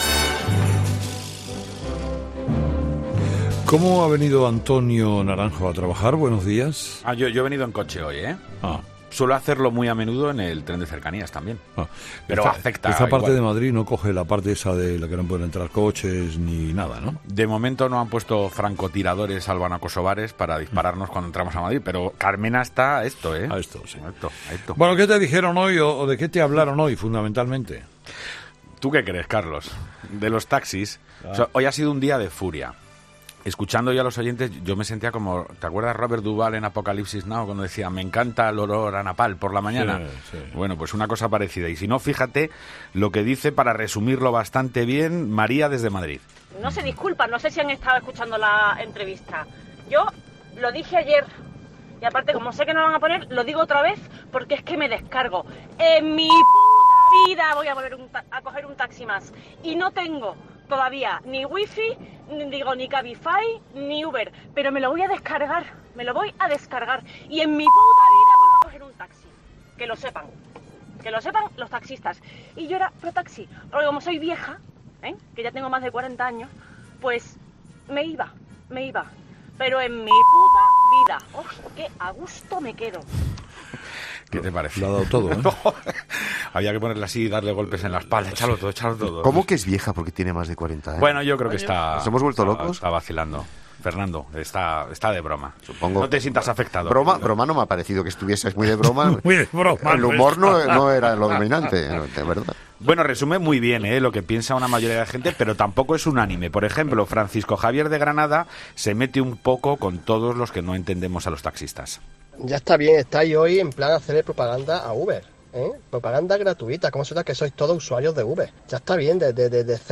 Un día más, ‘La tertulia de los oyentes’ se ha convertido en un debate muy encendido sobre la huelga indefinida de los taxistas.